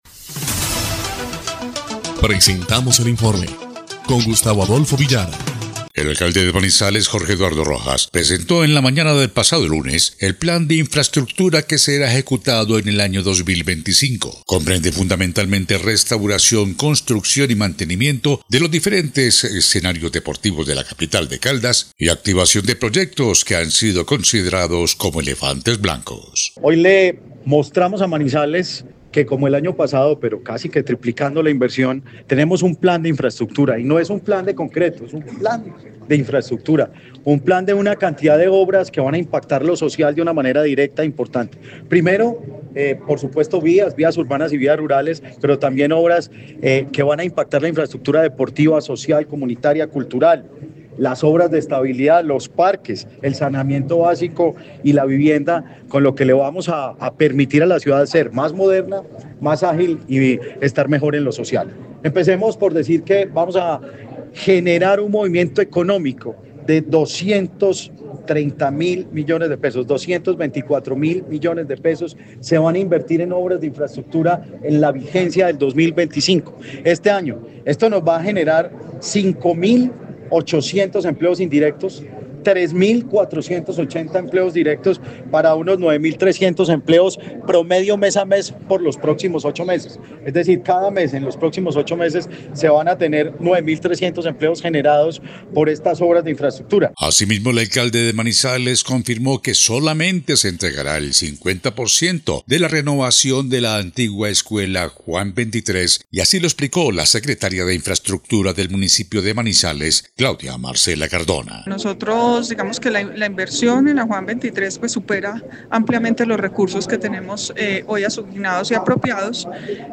EL INFORME 2° Clip de Noticias del 6 de mayo de 2025